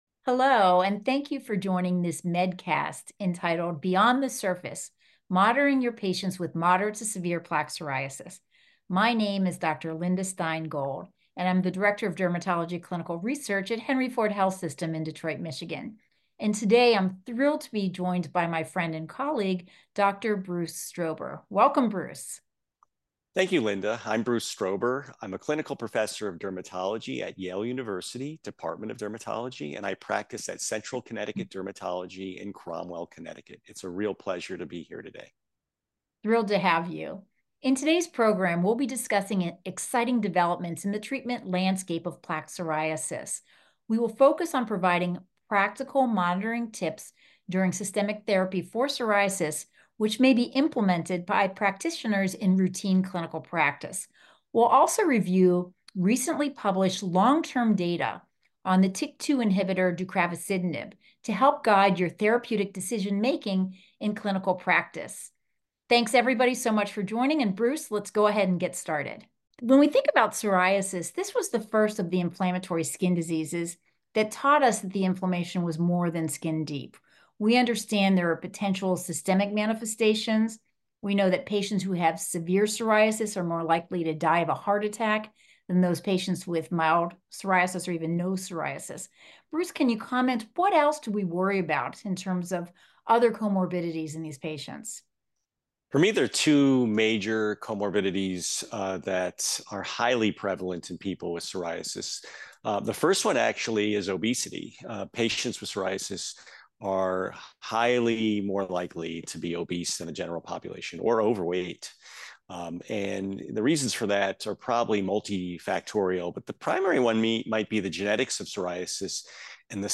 A panel of dermatologists have a comprehensive discussion on exciting developments in the treatment of plaque psoriasis, focusing on treatment approaches, patient monitoring strategies, and recent clinical trial data.